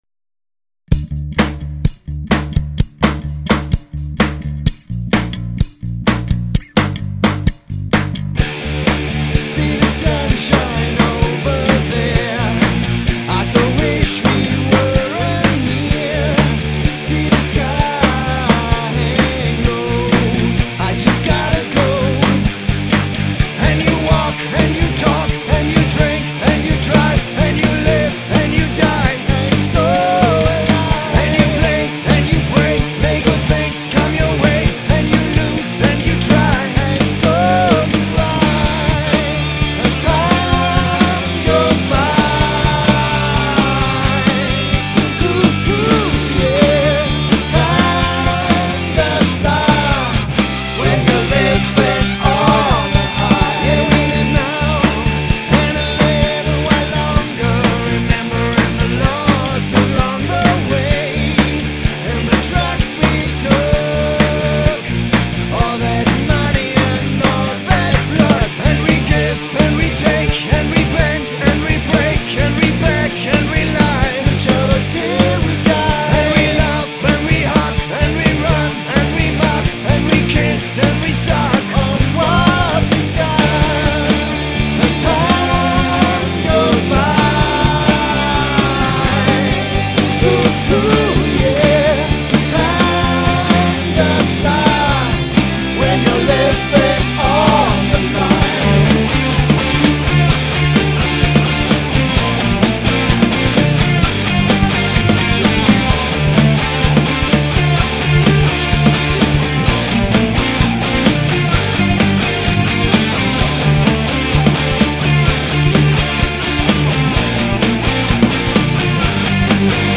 Recorded at MotherMoon Schopfheim and Mellsonic Steinen
Bass, Guitar, Vocals